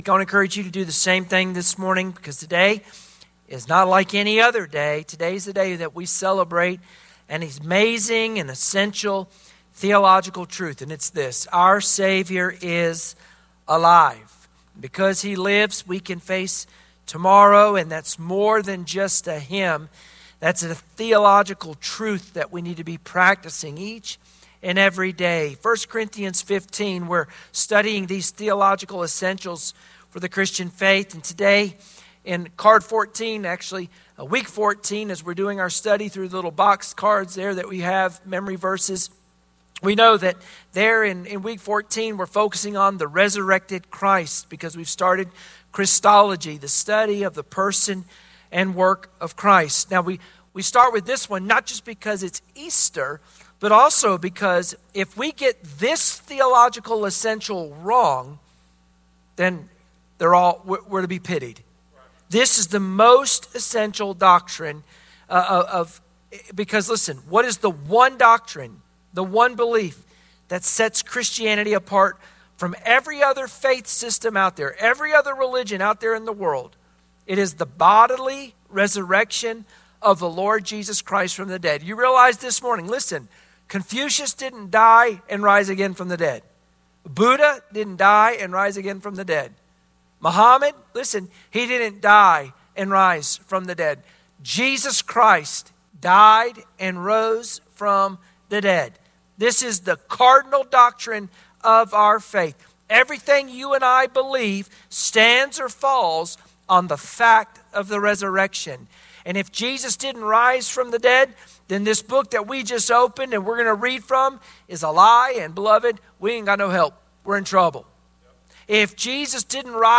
Sunday Morning Worship Passage: 1 Corinthians 15 Service Type: Sunday Morning Worship Share this